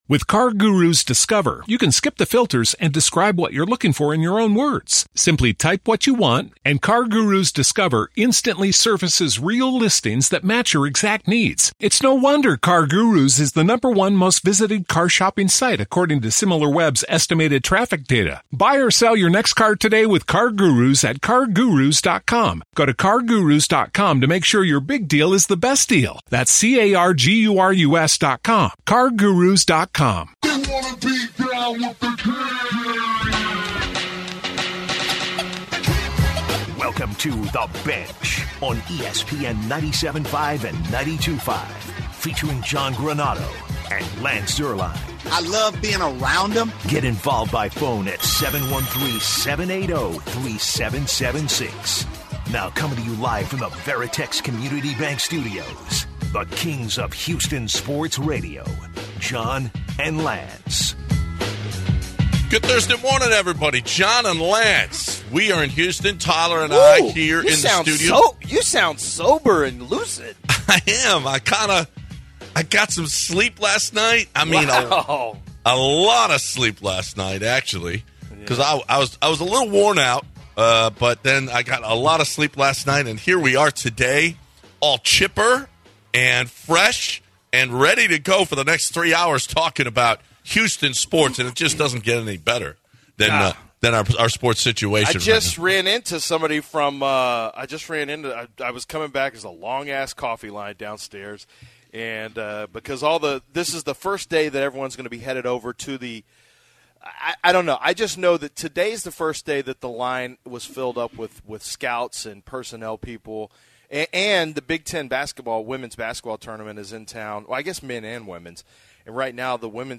live from the NFL Combine